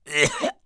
cough3.mp3